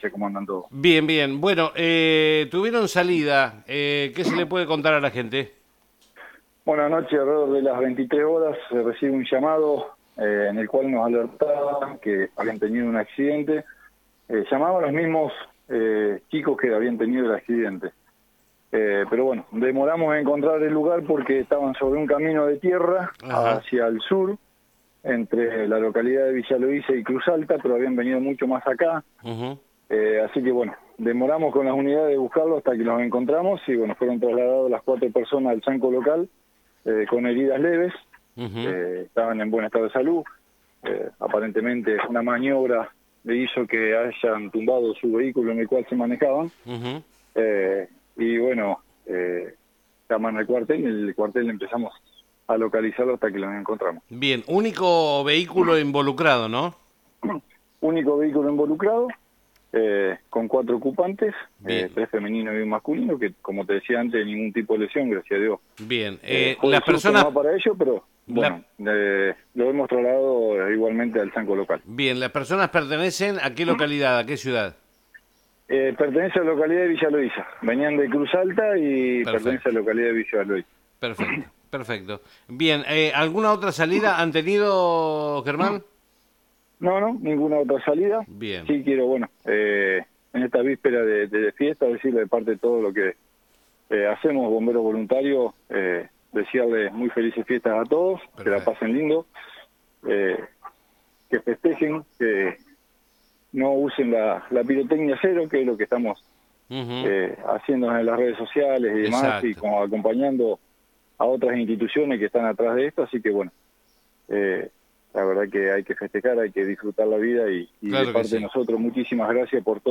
en la mañana de la FM SOL